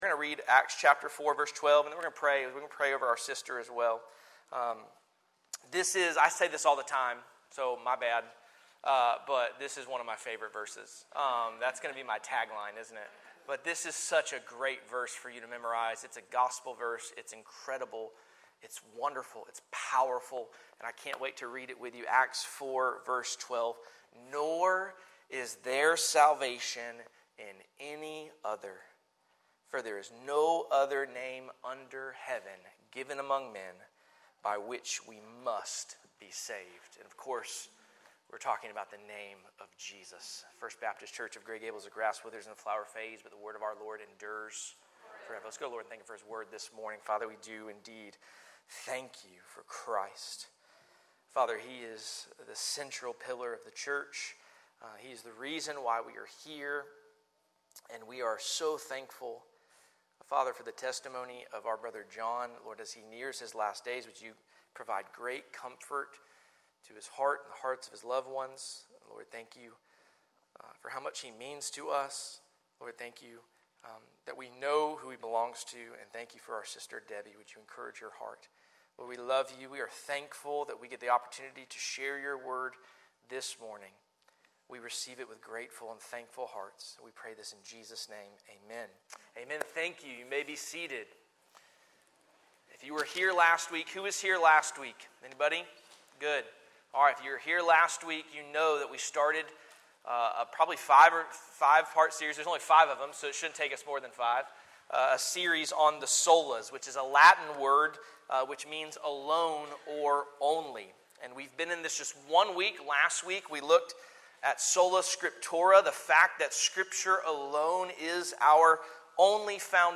Sermons | First Baptist Church of Gray Gables